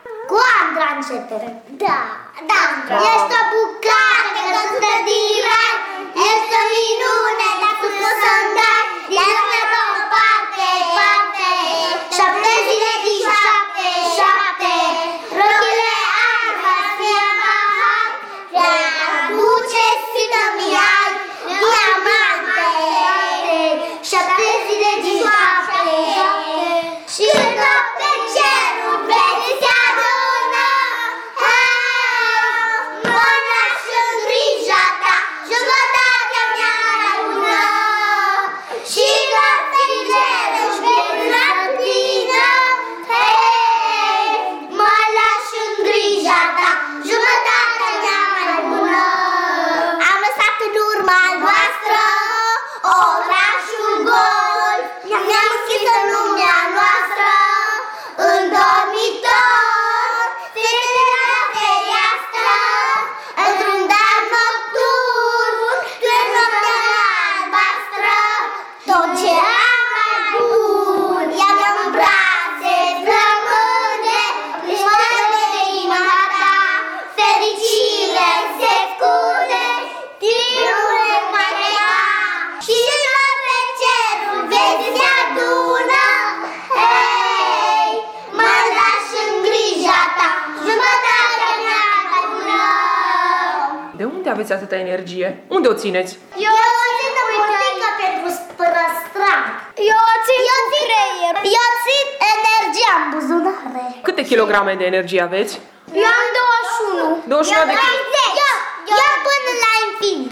Andra și 3SudEst ar fi de-a dreptul încântați să îi asculte pe preșcolarii de la grădinița Arlechino din Târgu Mureș, interpretând, în stil propriu, melodia „Jumătatea mea mai bună”.
Copiii debordează de energie, pe care o scot, spun ei, din buzunare.